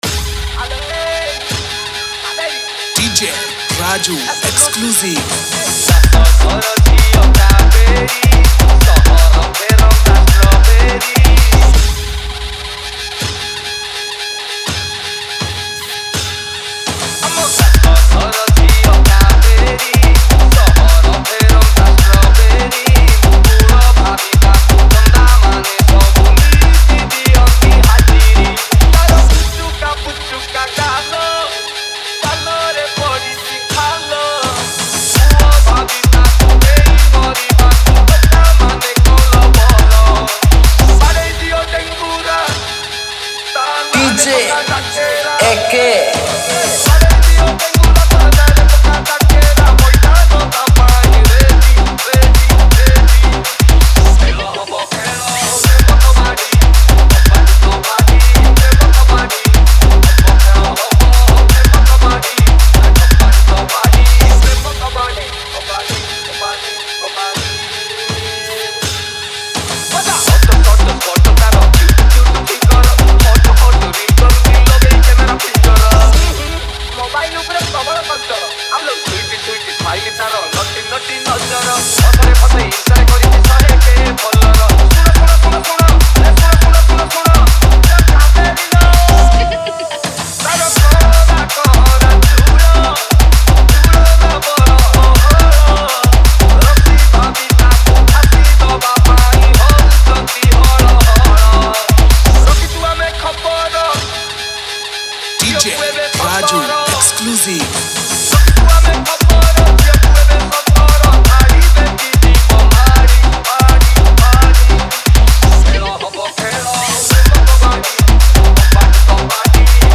Single Dj Song Collection 2022 Songs Download